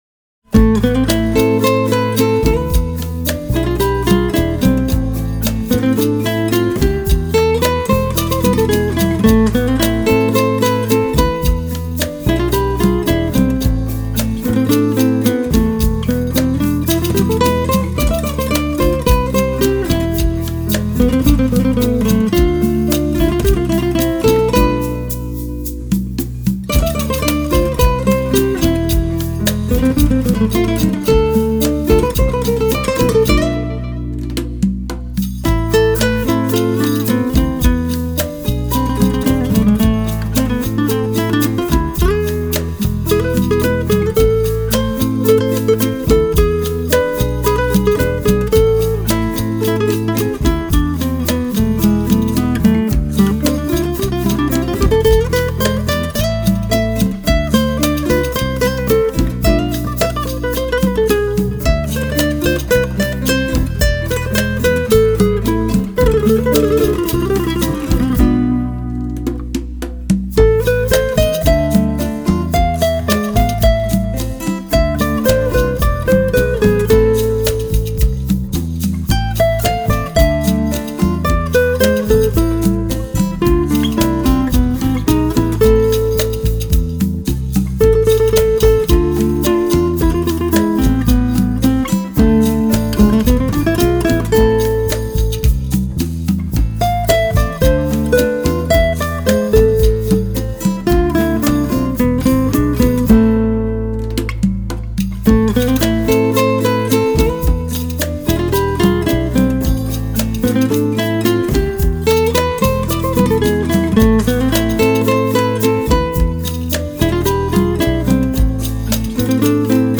سبک آرامش بخش , موسیقی بی کلام
موسیقی بی کلام فلامنکو